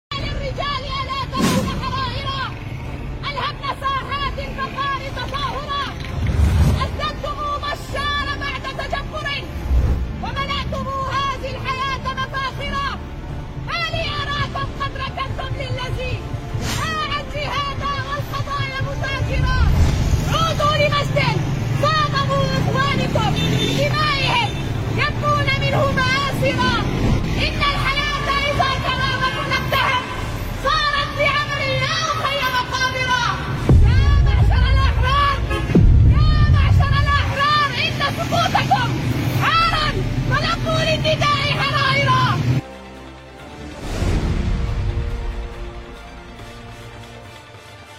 أبيات شعرية - يا للرجال؟